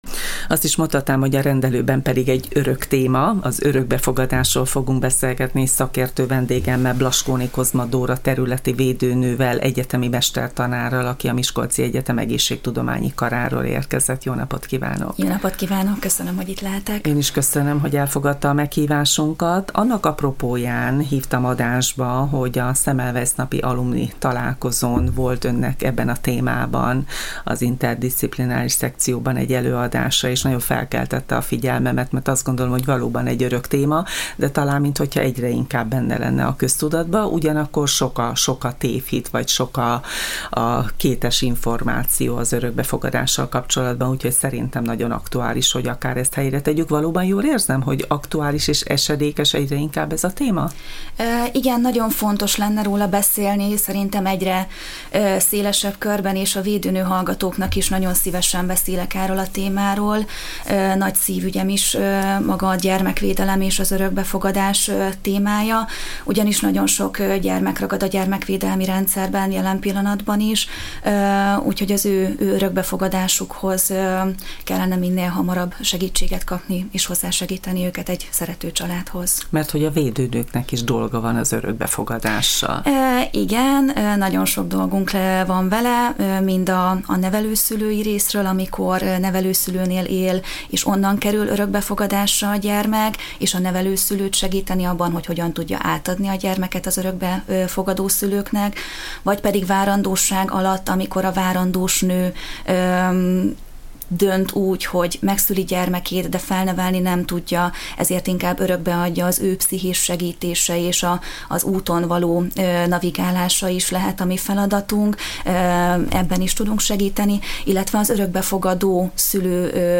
egyetemi mestertanár volt a vendégünk a témában. A szakember beszélt az örökbefogadás szabályozásáról, helyzetéről, a magyar gyermekvédelmi rendszer felépítéséről, valamint az örökbefogadás feltételeiről.